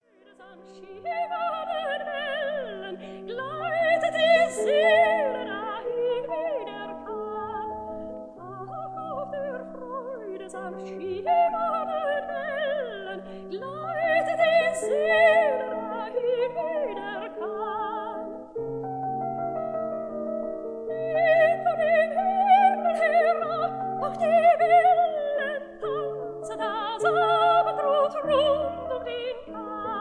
Sofiensaal, Vienna